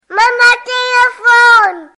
Kategorie: Klingeltöne